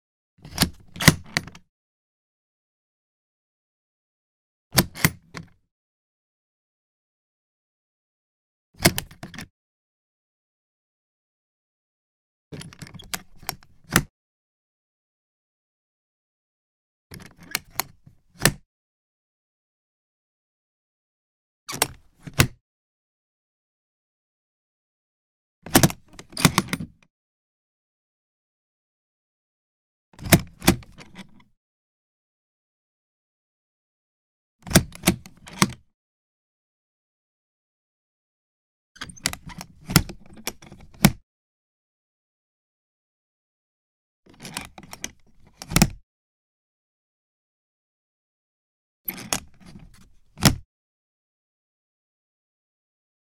household
Flight Case Unlock Single Latch